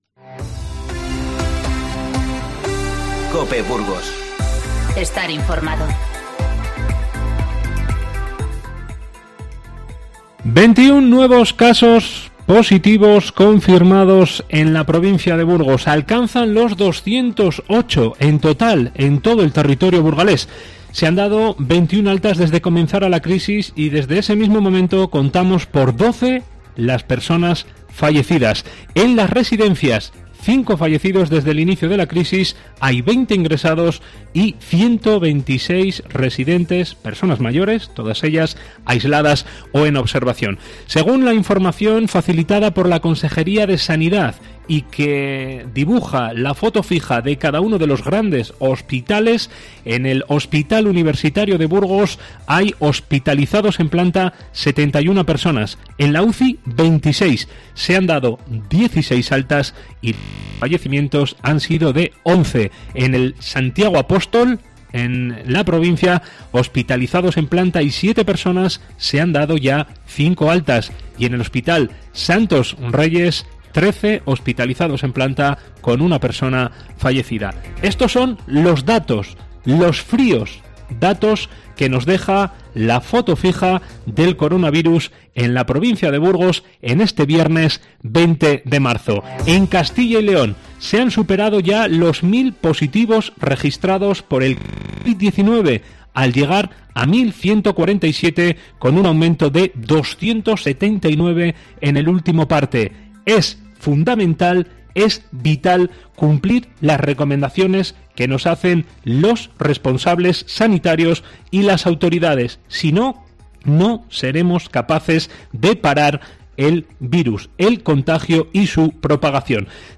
Informativo 20-03-20